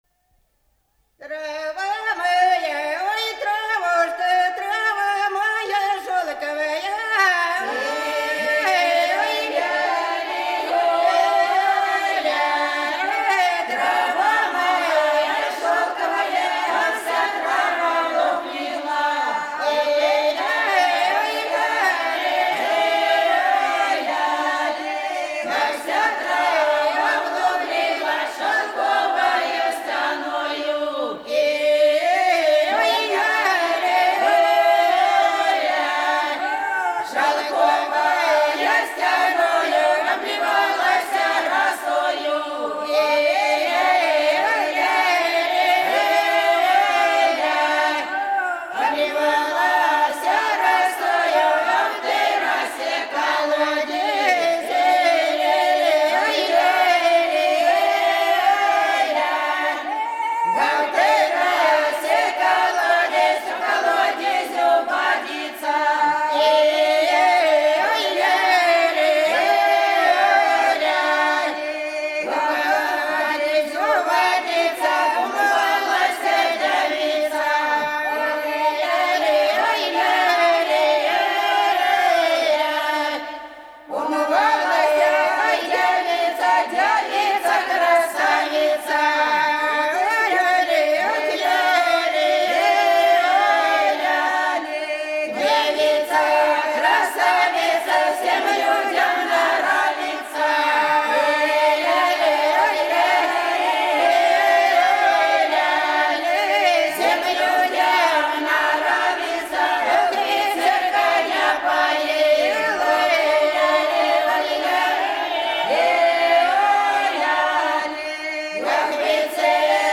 Голоса уходящего века (Курское село Илёк) Трава моя, травушка (плясовая, на Пасху)